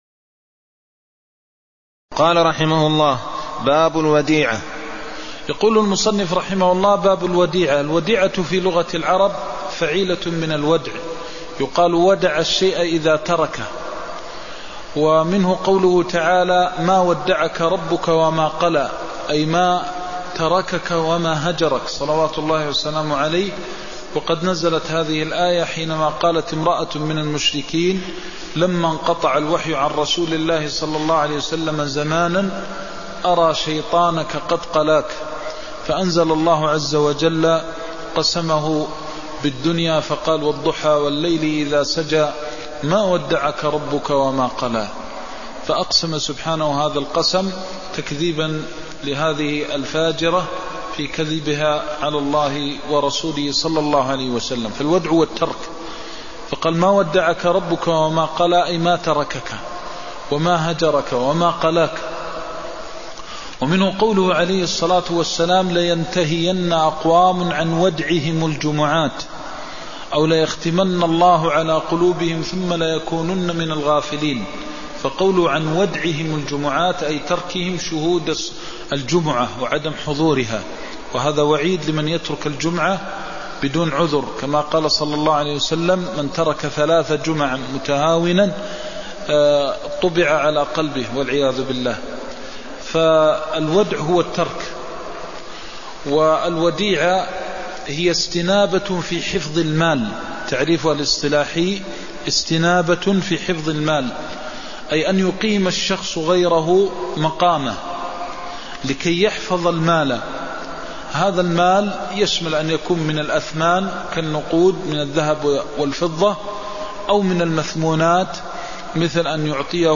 المكان: المسجد النبوي الشيخ: فضيلة الشيخ د. محمد بن محمد المختار فضيلة الشيخ د. محمد بن محمد المختار باب الوديعة (18)